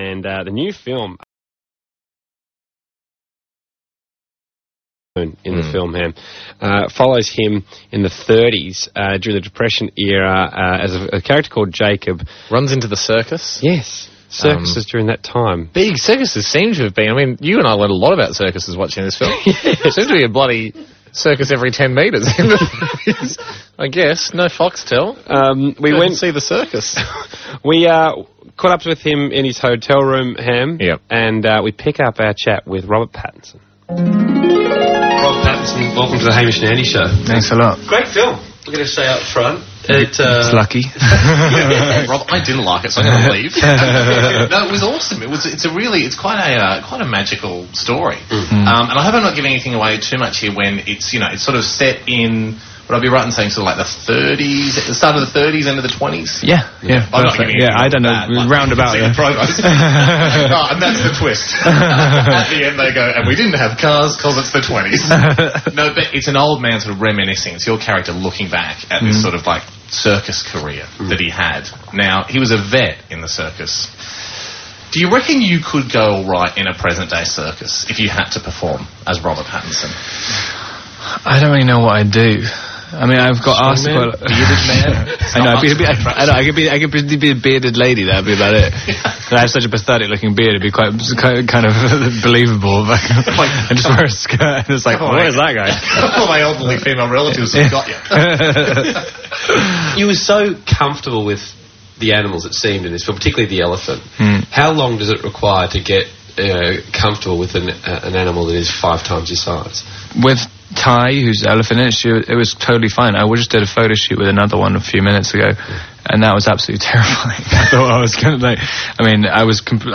*AUDIO and VIDEO* Robert Pattinson Interview with 91.9 Sea FM   3 comments
Here’s a radio interview with Rob with Hamish & Andy on Australian Radio